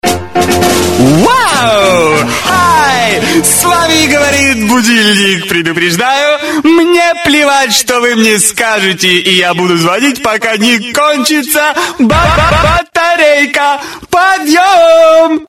Мелодия на будильник "С вами говорит будильник"